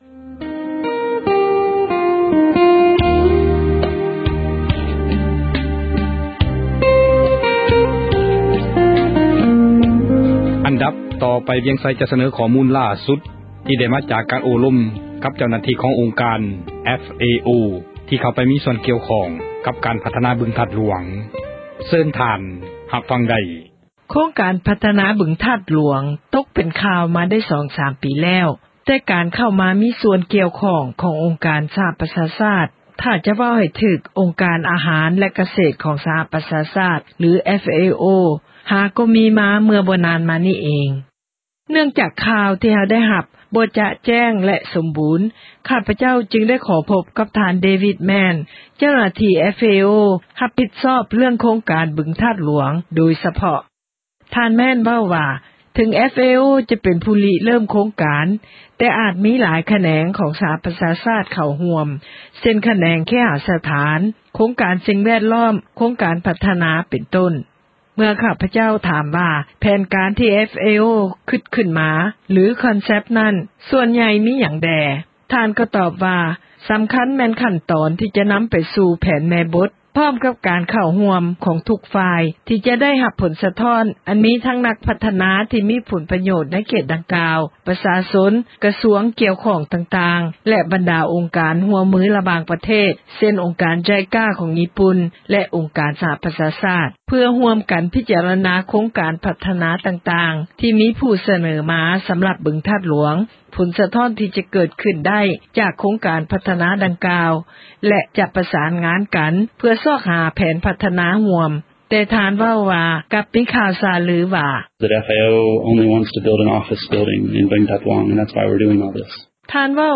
ສັມພາດເຈົ້າໜ້າທີ່ ອົງການ FAO